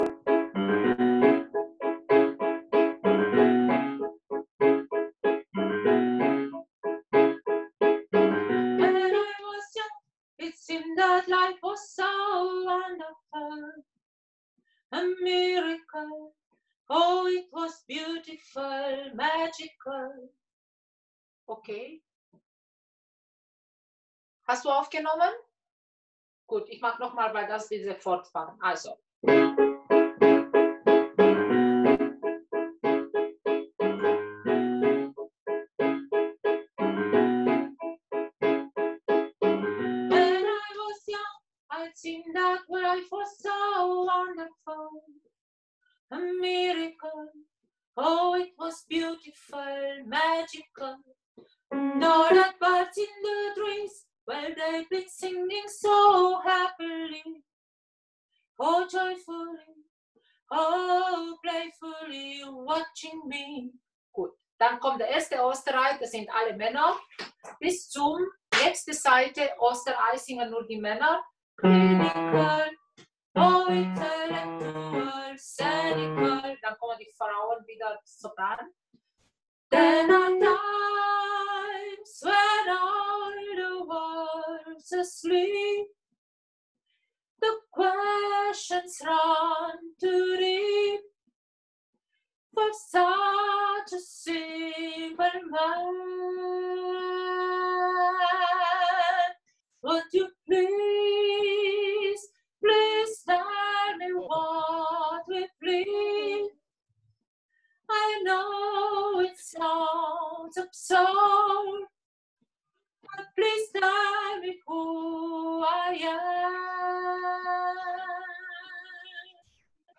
02 - Sopran - ChorArt zwanzigelf - Page 3